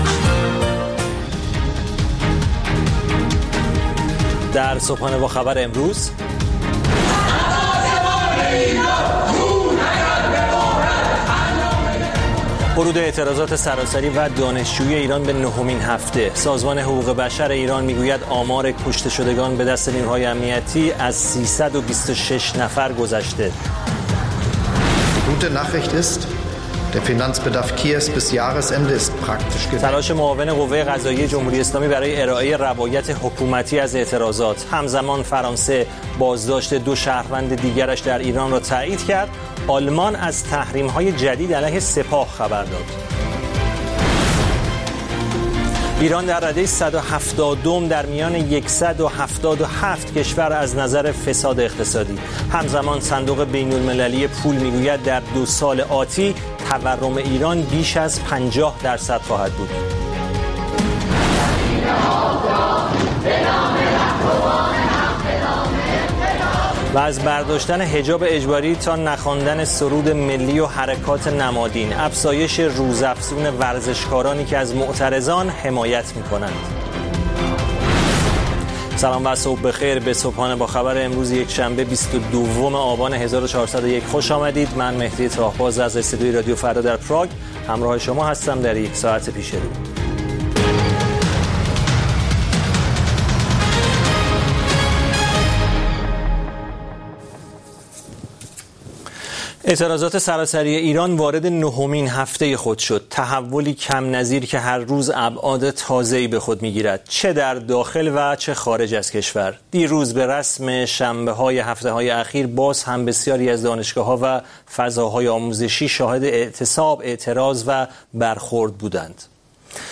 گزارشگران راديو فردا از سراسر جهان، با تازه‌ترين خبرها و گزارش‌ها، مجله‌ای رنگارنگ را برای شما تدارک می‌بينند. با مجله بامدادی راديو فردا، شما در آغاز روز خود، از آخرين رويدادها آگاه می‌شويد.